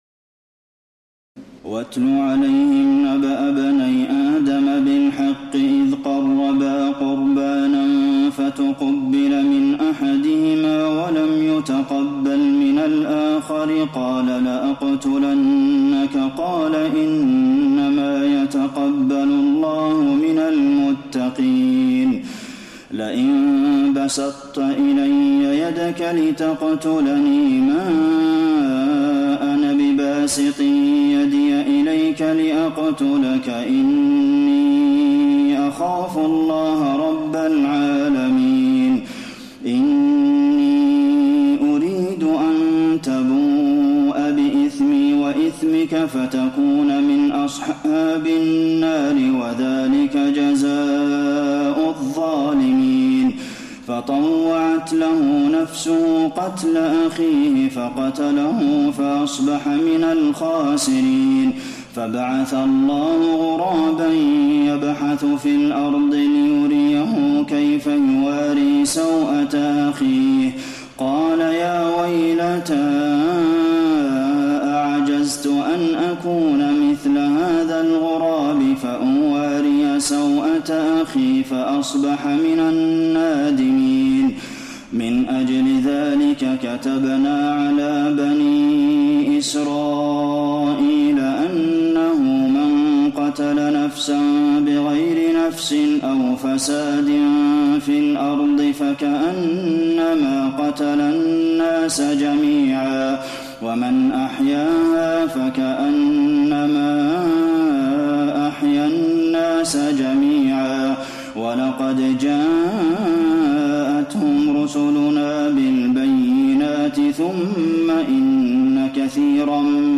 تراويح الليلة السادسة رمضان 1432هـ من سورة المائدة (27-81) Taraweeh 6 st night Ramadan 1432H from Surah AlMa'idah > تراويح الحرم النبوي عام 1432 🕌 > التراويح - تلاوات الحرمين